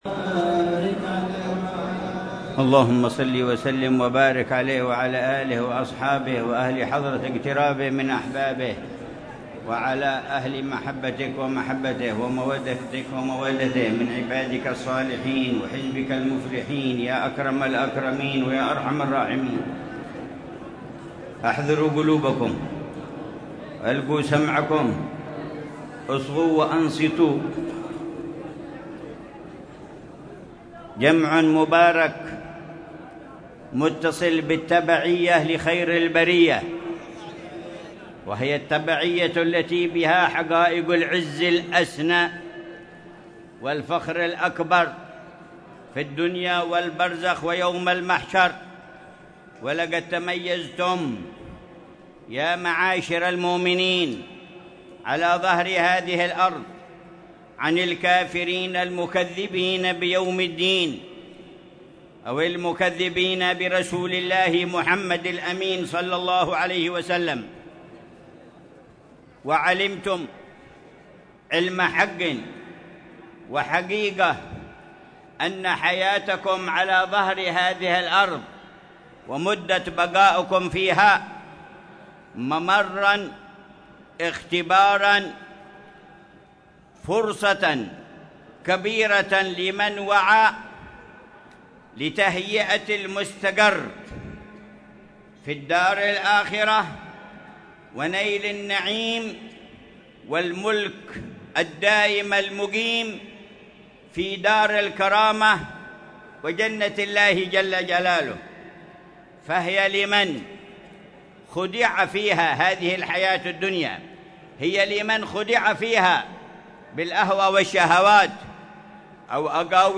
مذاكرة العلامة الحبيب عمر بن محمد بن حفيظ في مجلس عقد النكاح للزواج الجماعي الـ 26 الذي ينظمه رباط المصطفى بالشحر، عصر الثلاثاء 15 ربيع الثاني 1447هـ بعنوان: